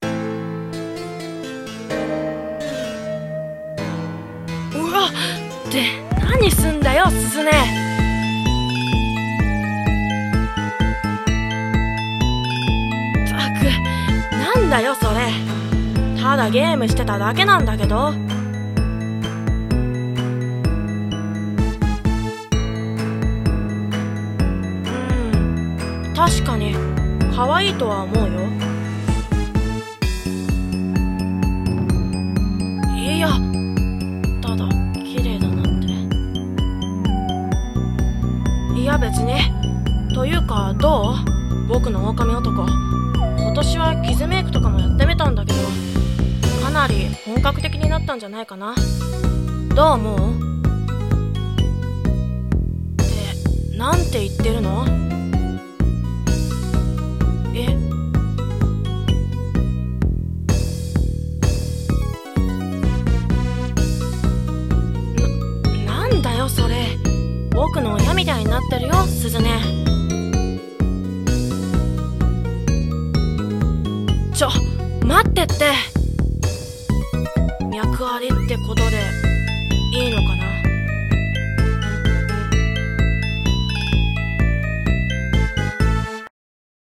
【ハロウィン声劇】かっこいい…